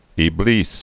(ē-blēs, ĕblĭs) or I·blis (ĭ-blēs)